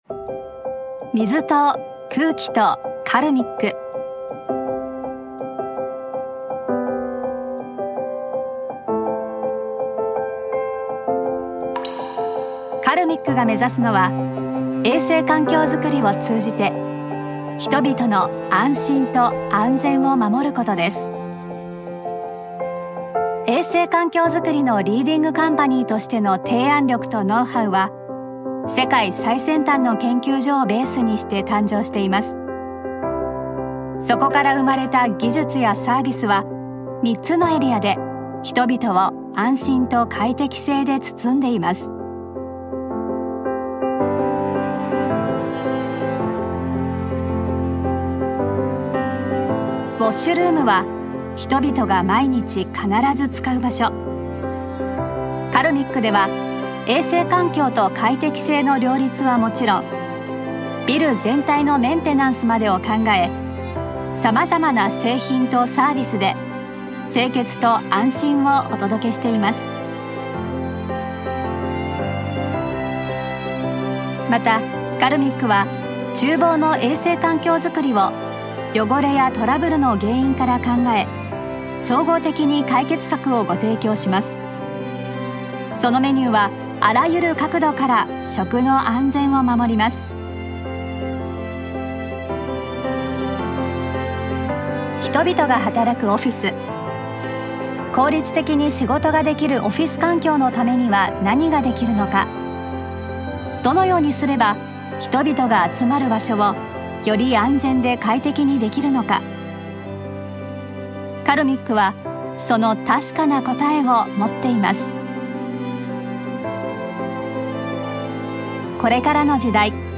2. Corporate 02:38
If you’re looking for a voiceover that is based in japan i have many years of experience, and i can help you with your next project.